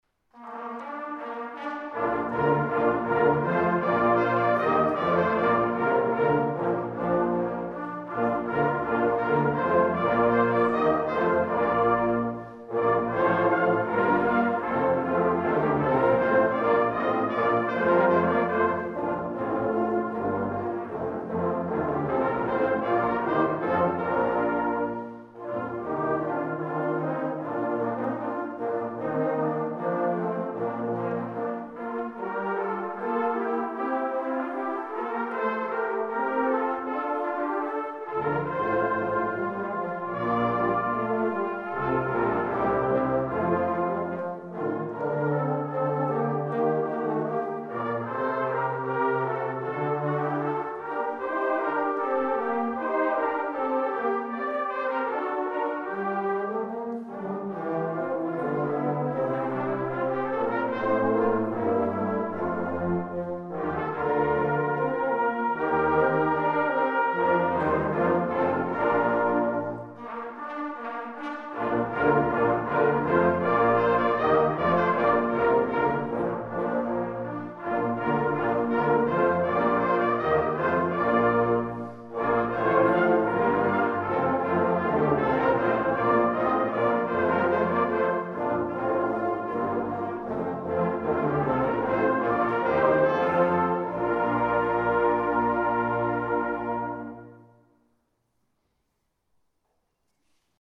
Bläsermusik proben
Die Aufnahmen wurden von verschiedenen Ensembles aufgenommen und zur Verfügung gestellt. Nicht mit dem Anspruch einer perfekten CD-Aufnahme, sondern als Hilfe für Chorleiterinnen und Chorleiter oder einzelne Mitspieler, um sich einen Klangeindruck der Stücke zu verschaffen.
Georg Philipp Telemann, Bläser des Bezirks Ortenau, Gloria S. 29